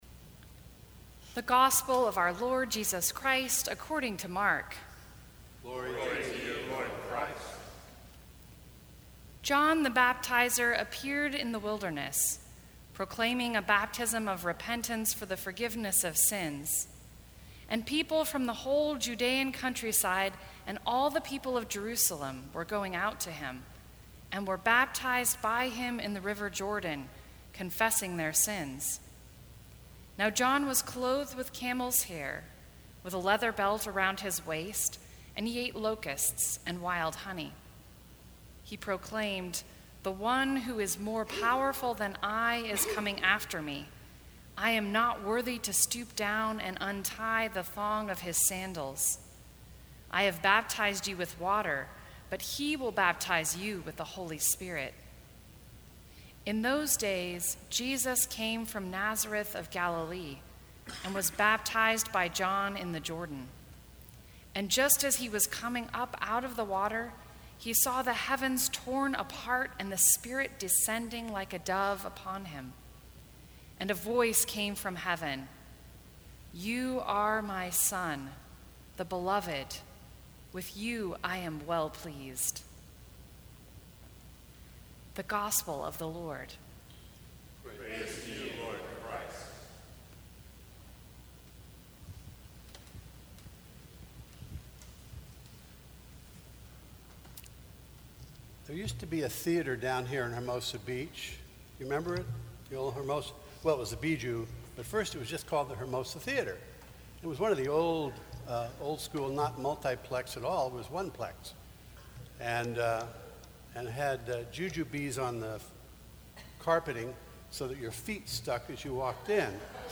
Sermons from St. Cross Episcopal Church The Waters of Baptism Jan 08 2018 | 00:11:08 1x 00:00 / 00:11:08 Subscribe Share Apple Podcasts Spotify Overcast RSS Feed Share Link Embed